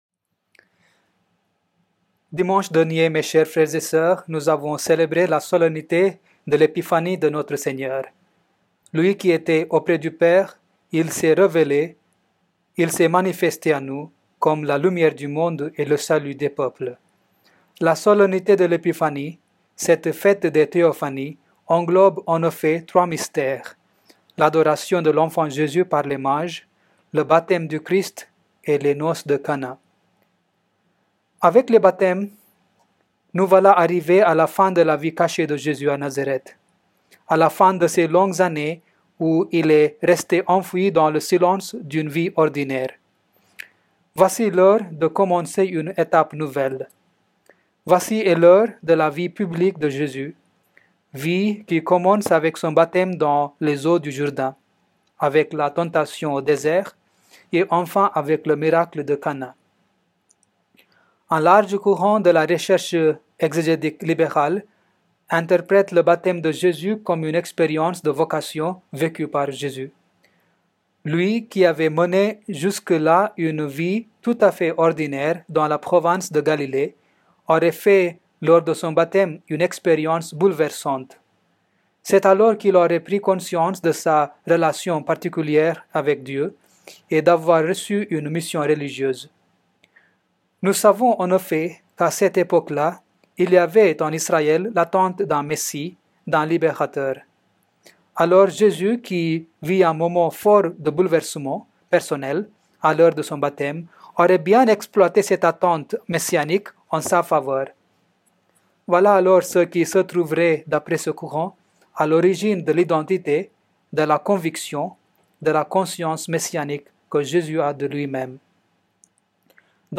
Enregistrement en direct